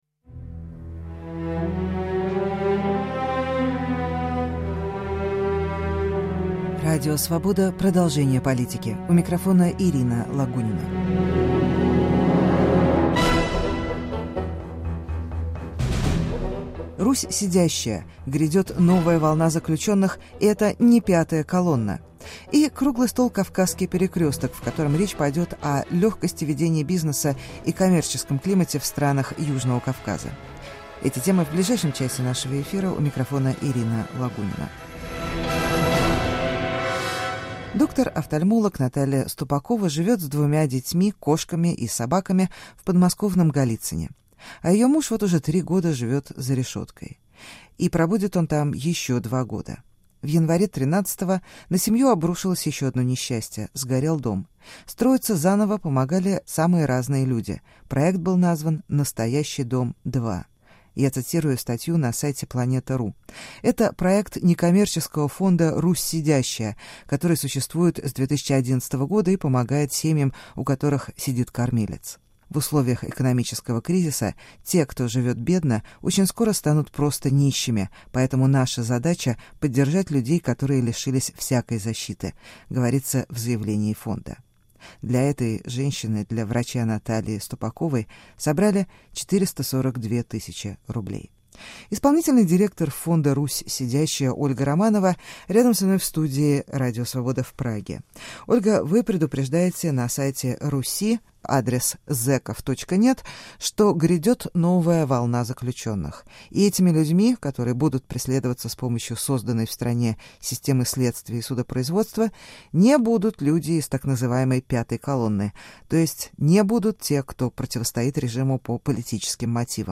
"Русь сидящая": грядет новая волна заключенных, и это не "пятая колонна". Круглый стол Кавказский перекресток – о легкости ведения бизнеса и коммерческом климате в странах Южного Кавказа.